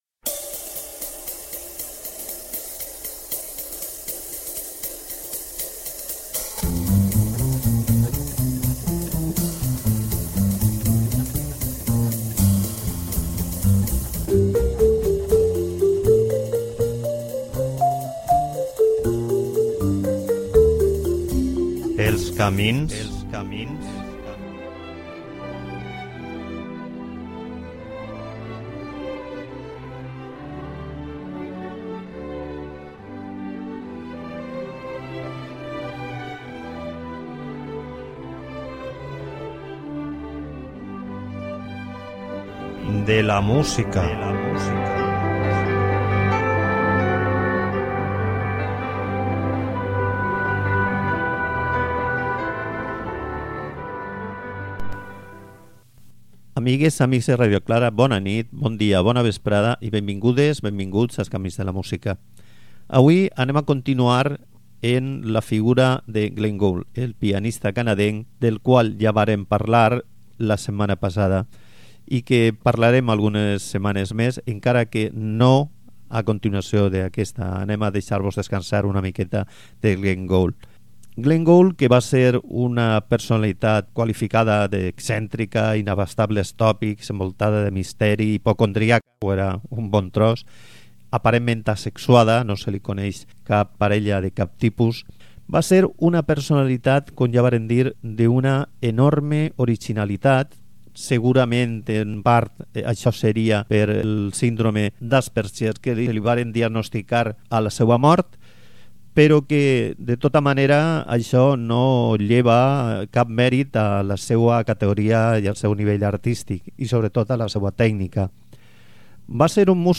En el cas de Beethoven aneu a escoltar una curiositat, la 5ª Simfonia de Beethoven, al piano, segons una versió de Franz Listz.